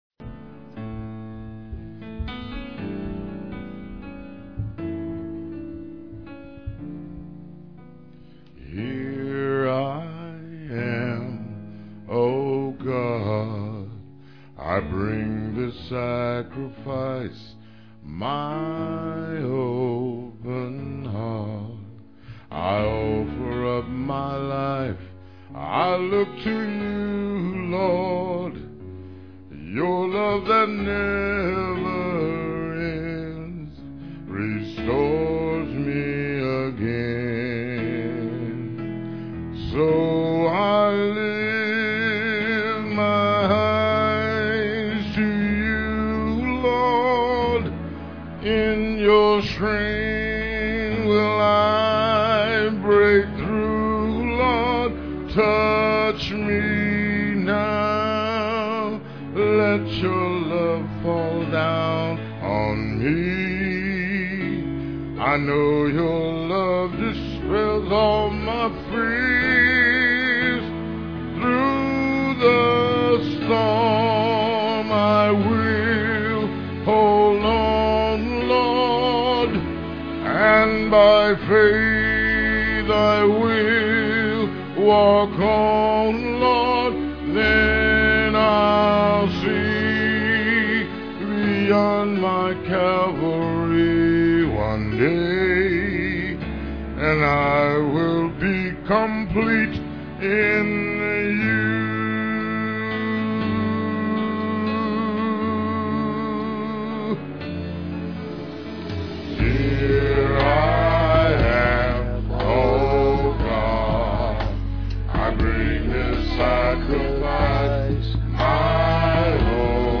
PLAY Thanks Living, Part 2, Nov 19, 2006 Scripture: Psalm 100:1-5. Scripture Reading
solo.